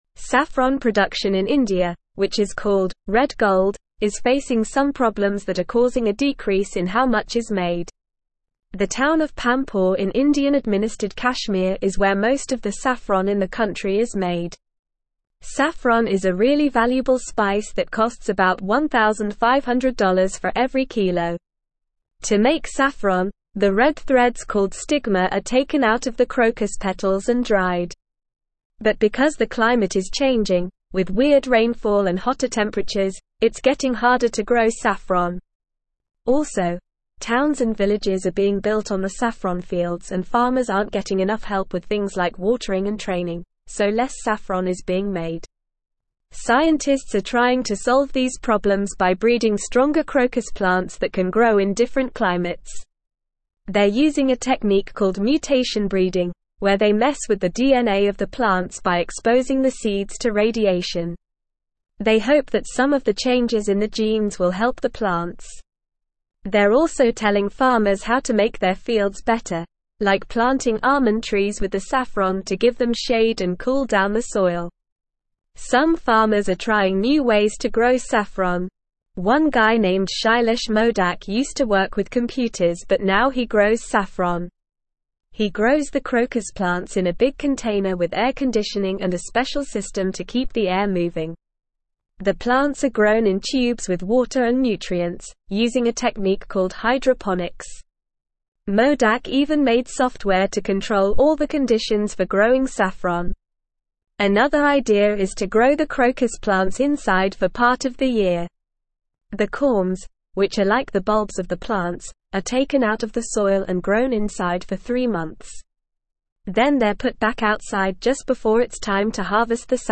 Normal
English-Newsroom-Upper-Intermediate-NORMAL-Reading-Saffron-production-in-India-challenges-and-solutions.mp3